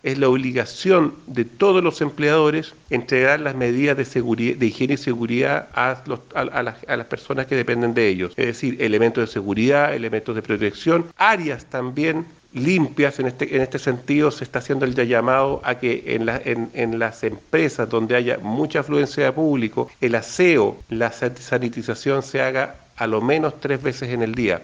El seremi del Trabajo, Fernando Gebhard, comentó que los empleadores tienen obligaciones para velar por la salud de los trabajadores.